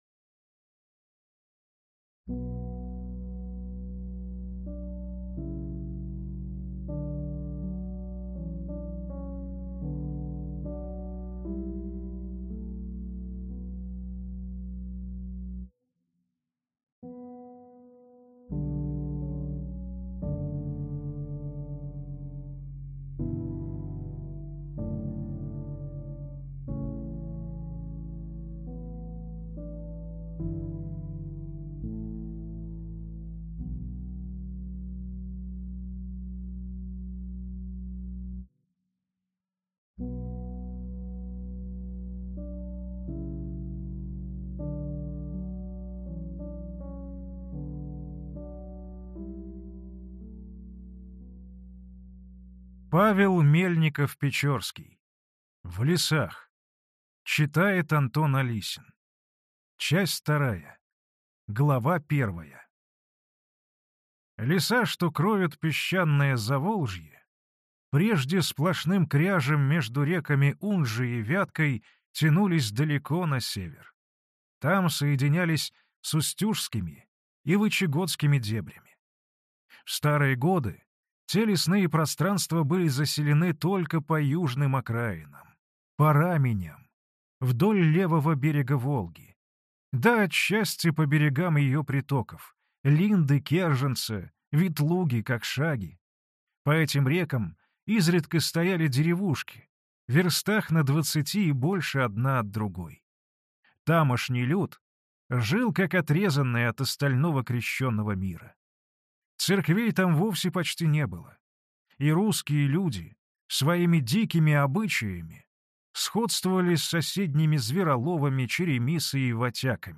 Аудиокнига В лесах (Часть 2) | Библиотека аудиокниг